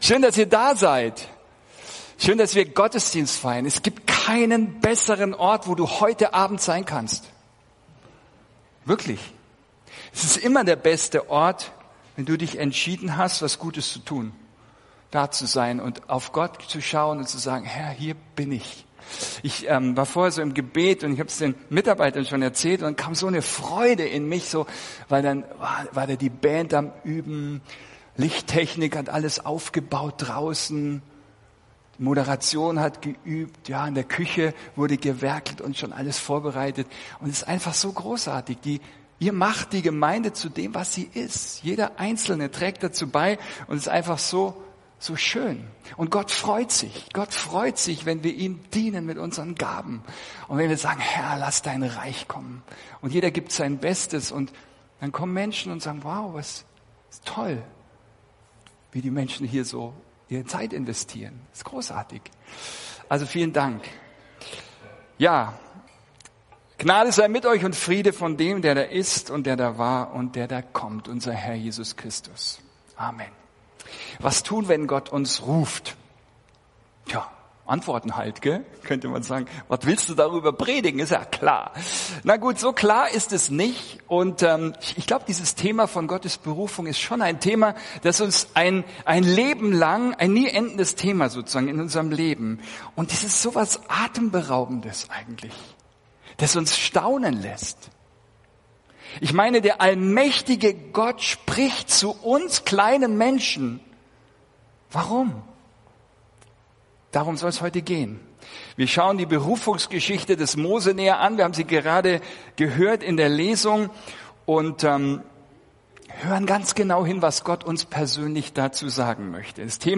Ein Studienblatt zur Predigt ist im Ordner “Notizen” (Dateien zum Herunterladen) verfügbar